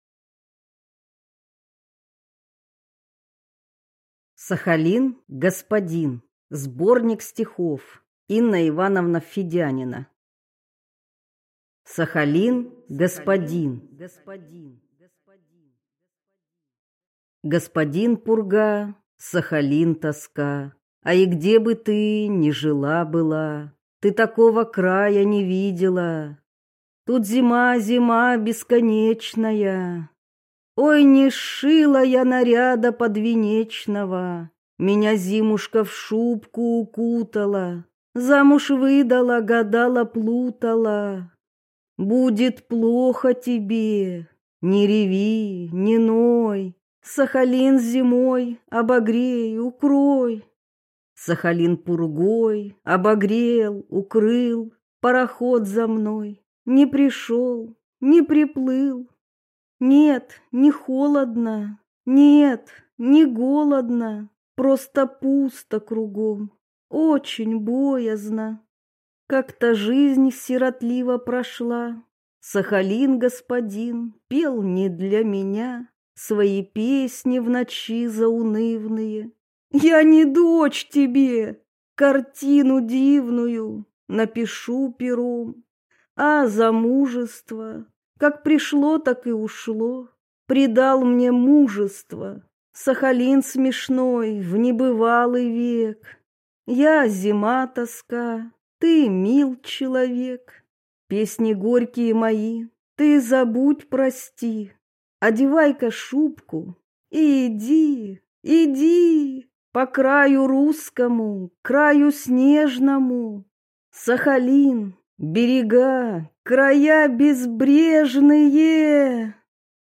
Аудиокнига Сахалин-господин. Стихи | Библиотека аудиокниг